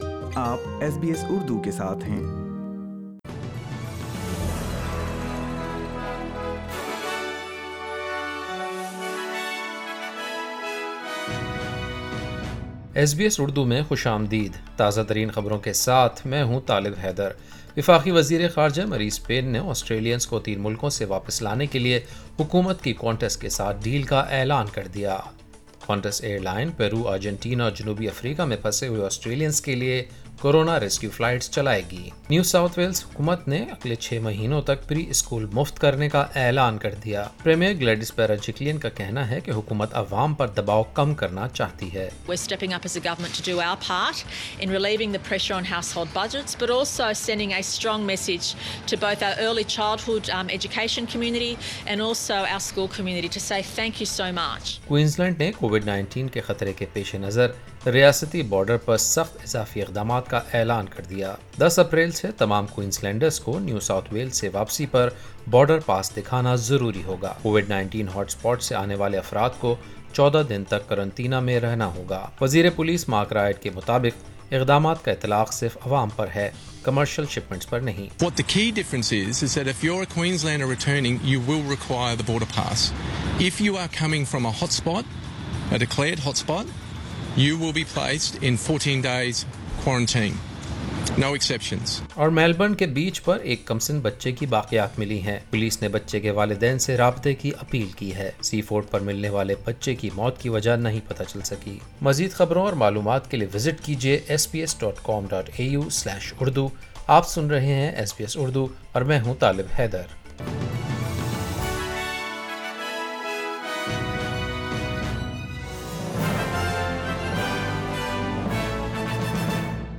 ایس بی ایس اردو خبریں ۹ اپریل ۲۰۲۰